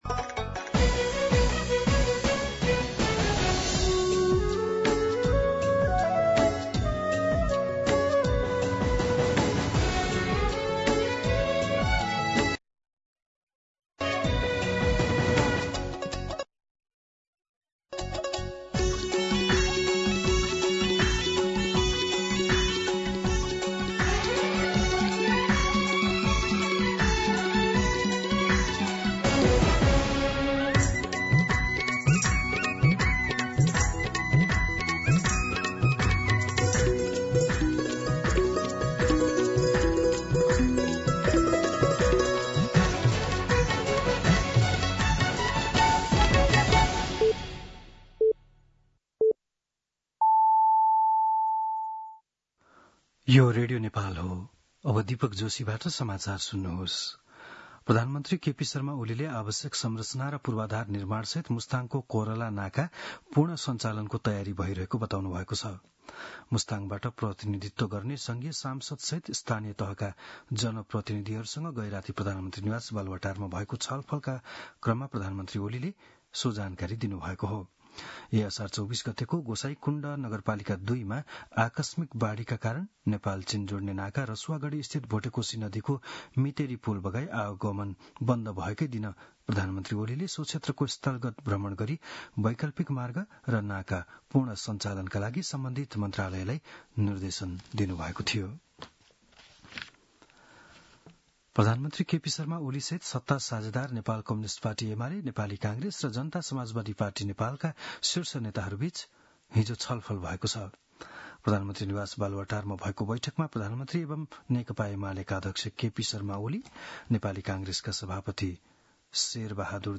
बिहान ११ बजेको नेपाली समाचार : ३१ असार , २०८२